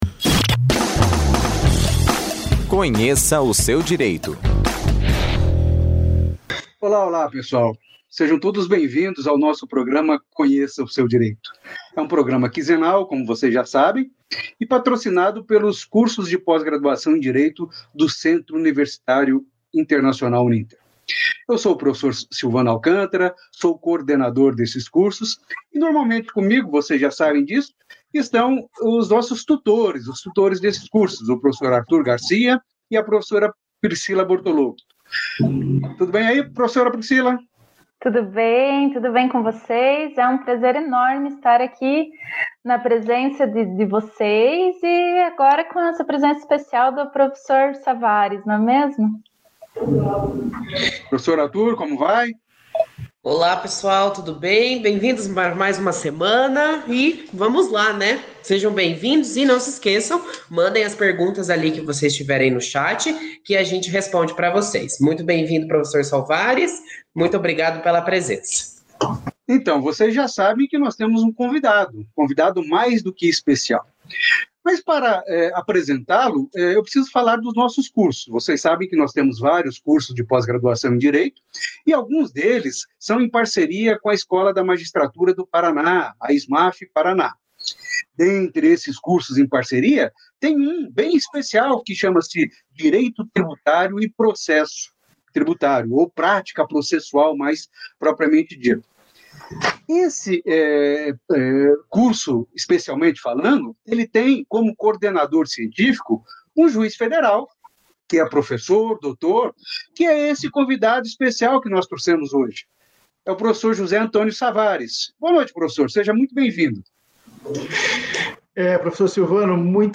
No dia 30 de junho tivemos alterações no Decreto da Previdência Social, e para esclarecer todas as suas dúvidas, este episódio do Conheça seu Direito promove uma conversa com o Juiz Federal José Antônio Savaris.